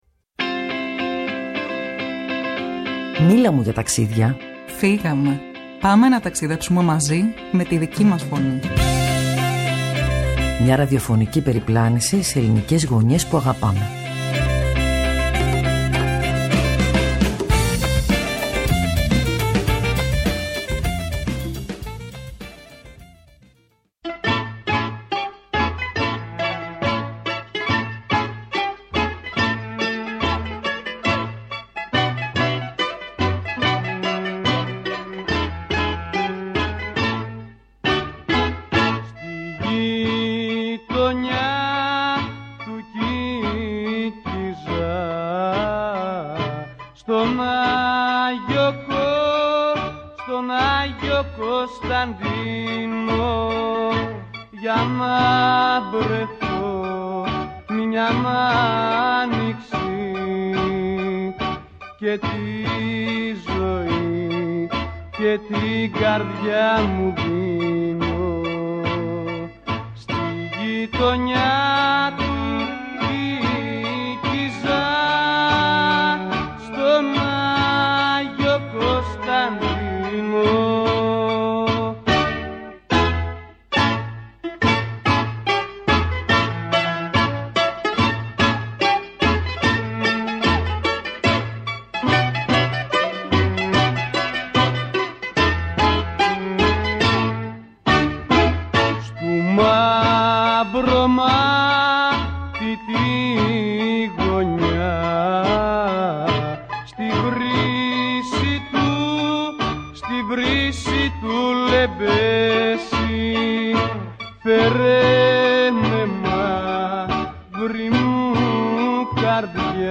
Καλεσμένη στο στούντιο